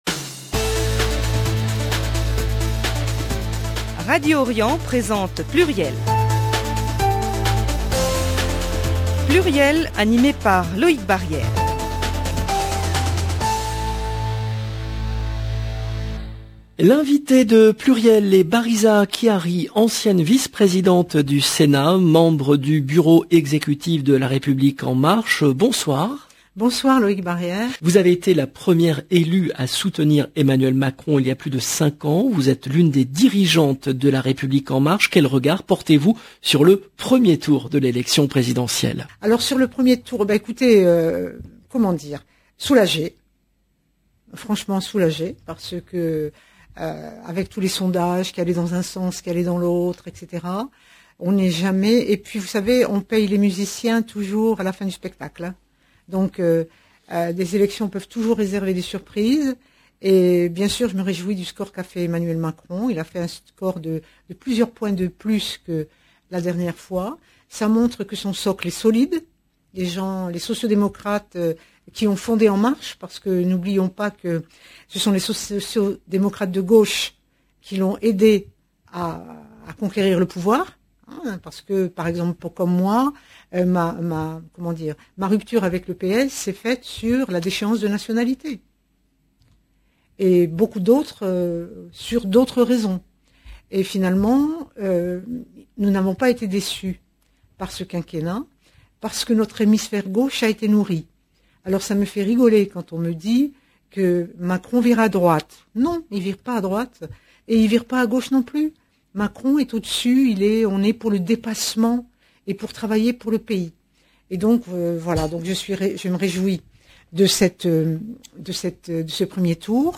L’invitée de Pluriel est Bariza Khiari, ancienne vice-présidente du Sénat, membre du Bureau Exécutif de LREM